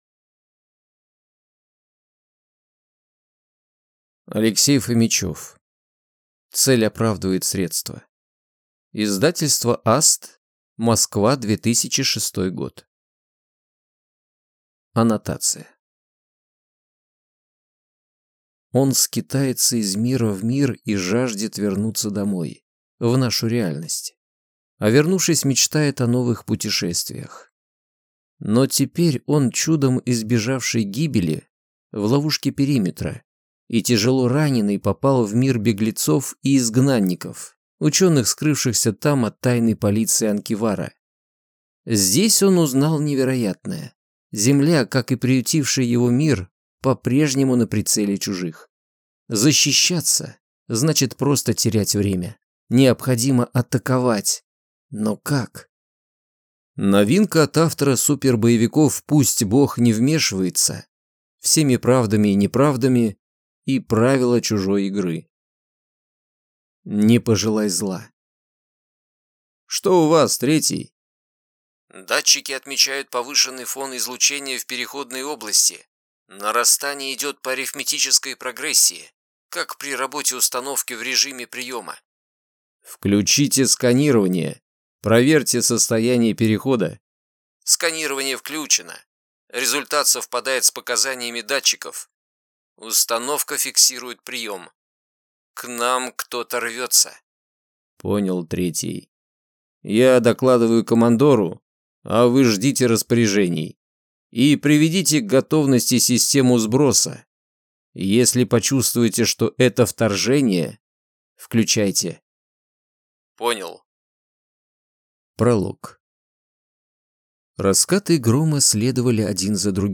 Аудиокнига Цель оправдывает средства | Библиотека аудиокниг